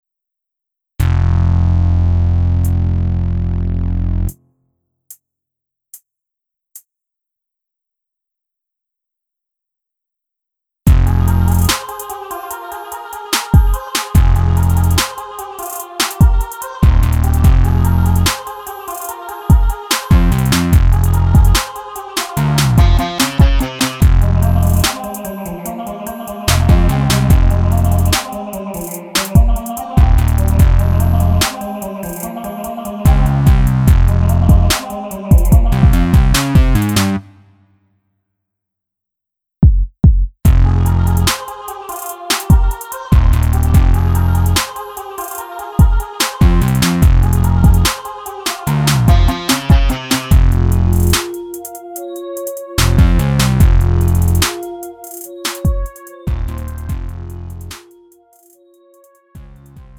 음정 원키 2:49
장르 가요 구분